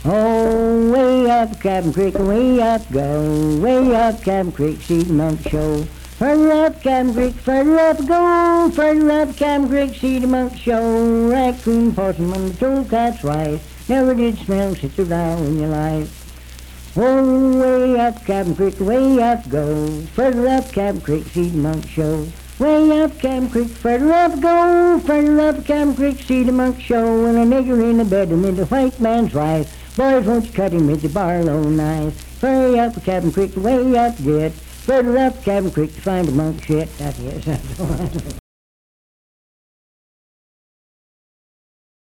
Unaccompanied vocal music
Verse-refrain 2(2)&R(4). Performed in Sandyville, Jackson County, WV.
Voice (sung)